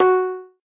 minecraft / sounds / note / pling.ogg
pling.ogg